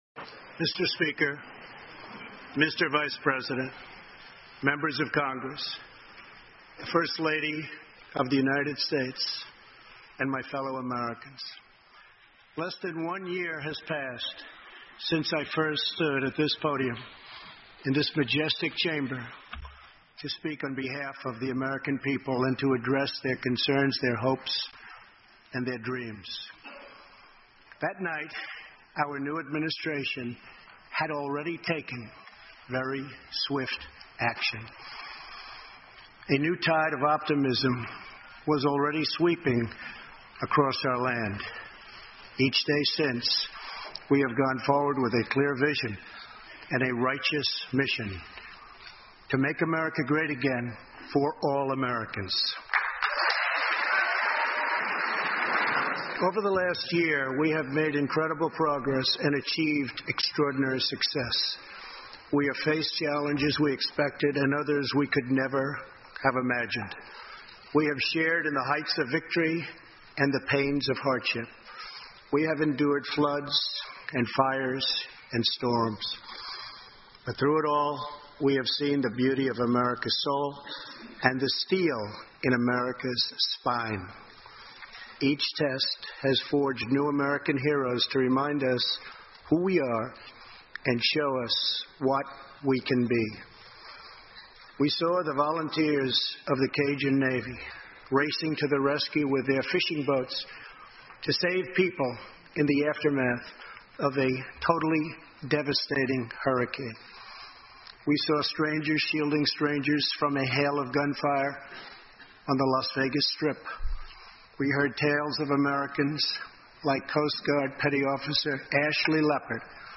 欧美名人演讲 第期:美国总统川普首次国情咨文演讲() 听力文件下载—在线英语听力室